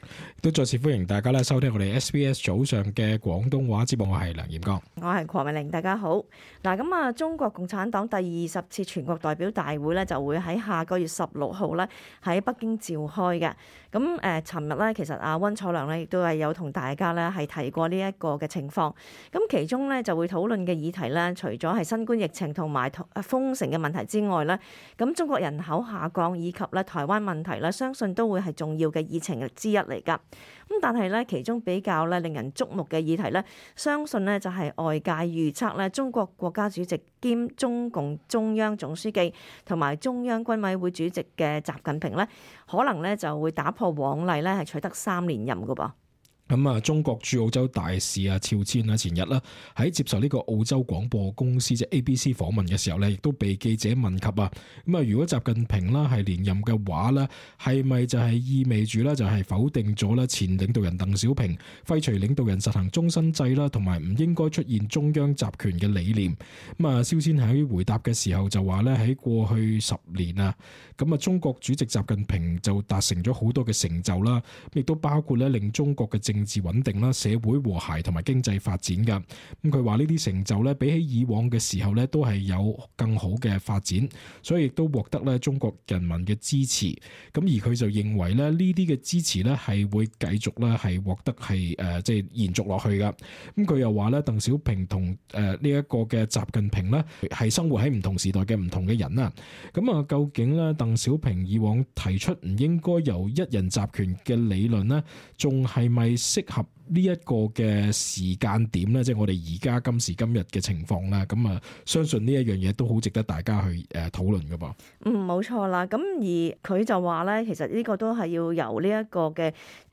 cantonese-talkback-sept-8-upload.mp3